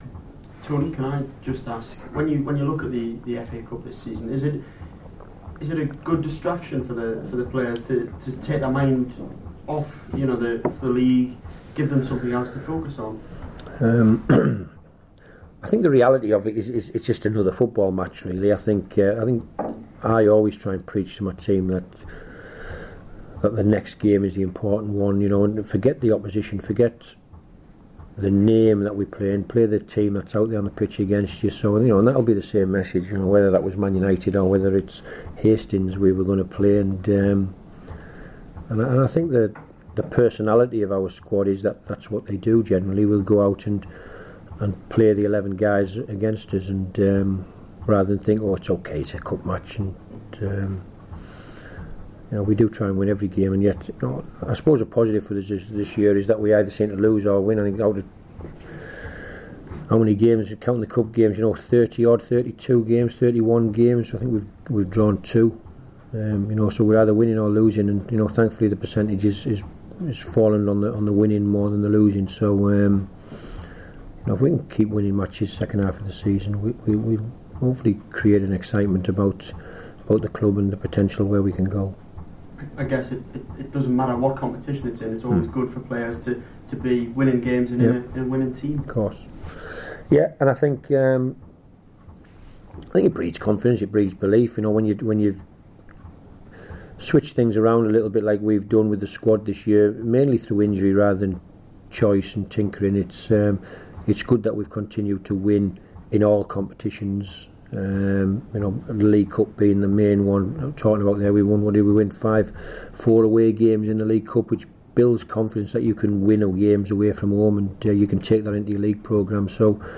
My chat with the Middlesbrough Manager for TFM Radio News.